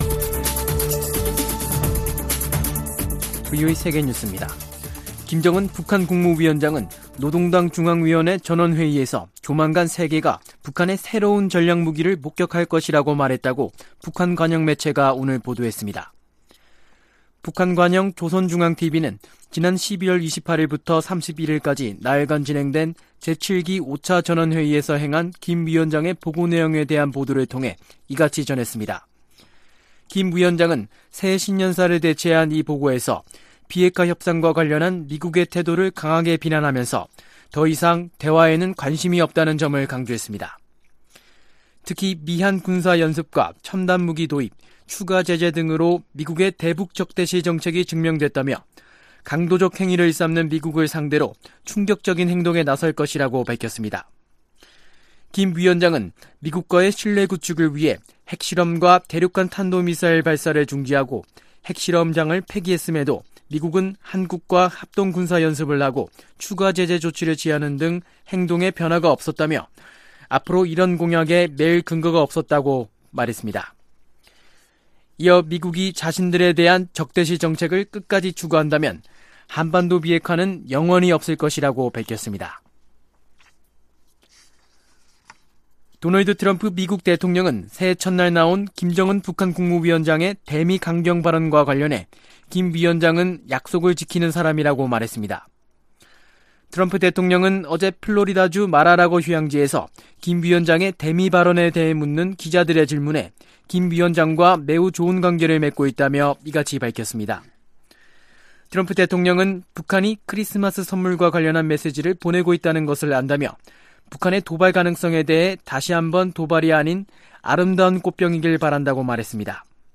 VOA 한국어 간판 뉴스 프로그램 '뉴스 투데이', 2019년 12월 11일 3부 방송입니다. 김정은 북한 국무위원장은 당 전원회의 보고에서 핵무기와 대륙간탄도미사일 시험발사 중단 등 조치를 계속 이행할 이유가 없다고 밝혔습니다. 도널드 트럼프 미국 대통령은 김정은 위원장과의 좋은 관계를 강조하면서 거듭 신뢰를 표명했습니다.